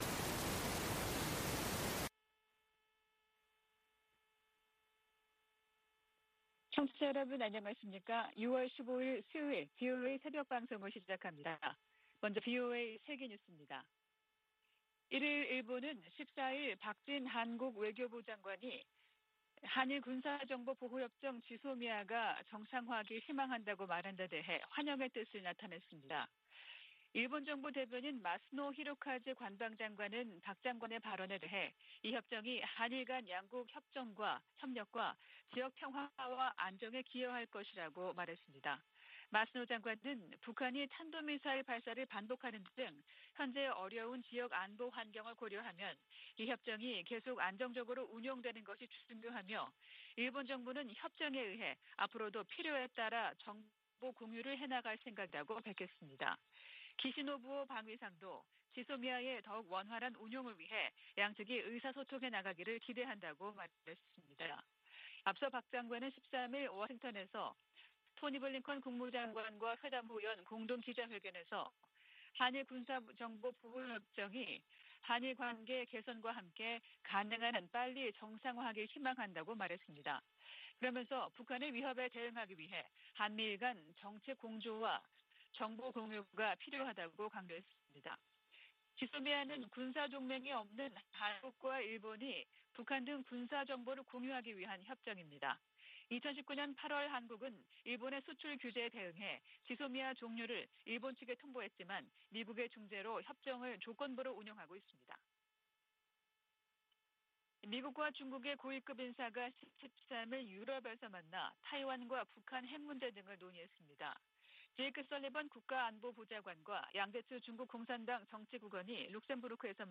VOA 한국어 '출발 뉴스 쇼', 2022년 6월 15일 방송입니다. 토니 블링컨 미 국무장관은 북한 핵실험에 단호한 대응을 예고하면서, 대화 응하지 않으면 압박을 강화할 것이라고 경고했습니다. 북한이 '강대강 정면투쟁' 원칙을 내세움에 따라 미-한-일 세 나라는 안보 협력을 강화하는 양상입니다. 북한의 방사포 역량이 핵무기 탑재가 가능한 미사일급으로 증대됐다고 미국의 전문가들이 진단했습니다.